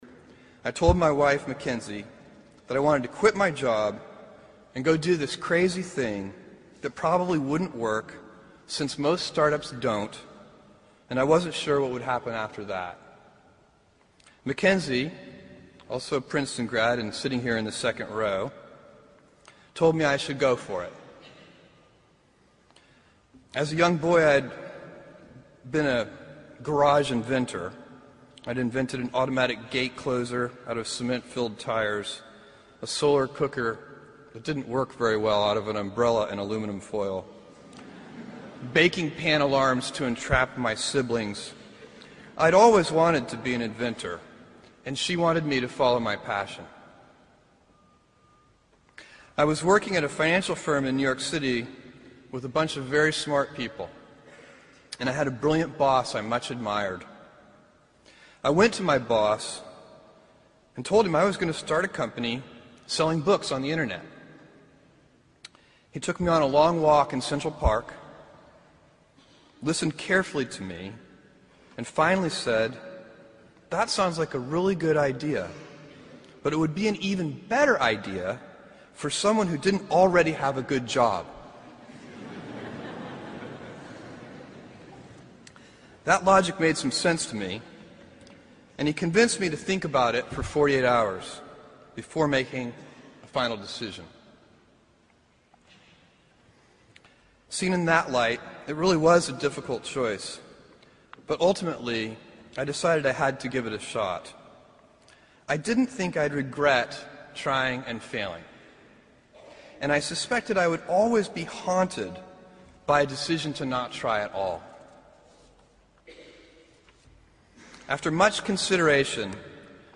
名人励志英语演讲 第113期:选择塑造人生(5) 听力文件下载—在线英语听力室